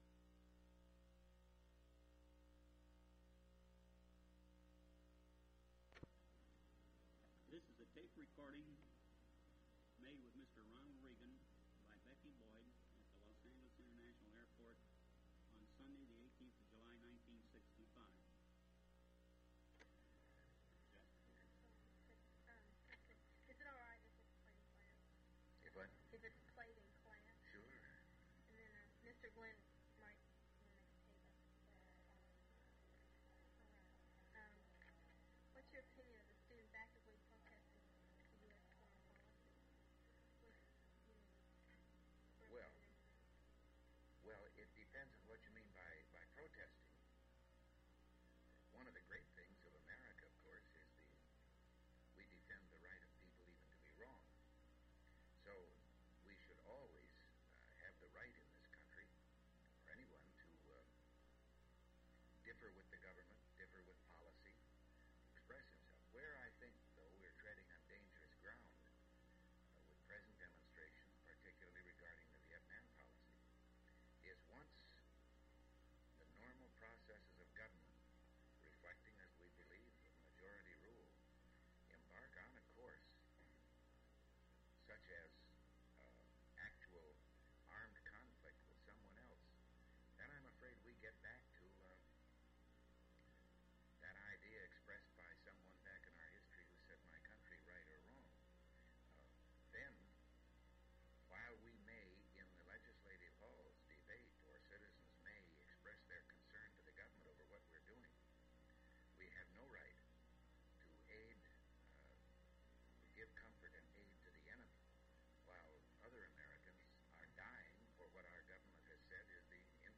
Reel to Reel Audio.